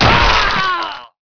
1 channel
death2.wav